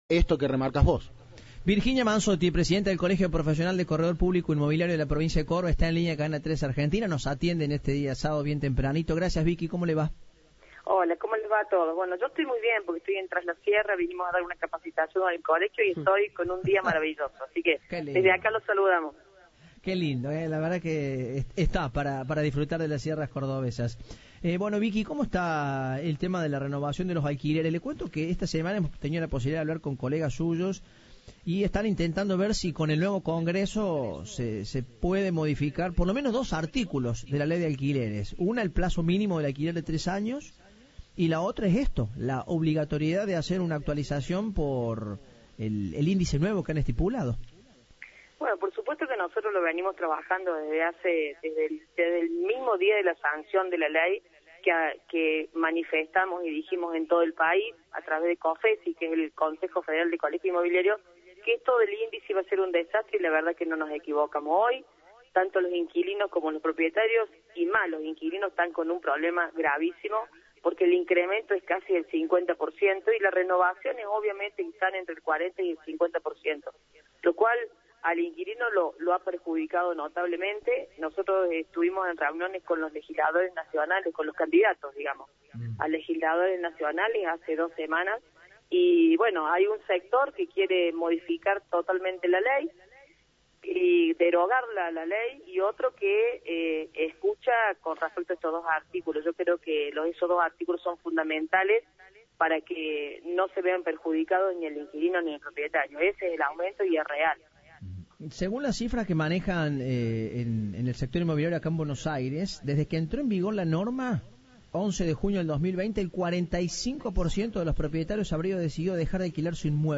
Entrevista de Informados.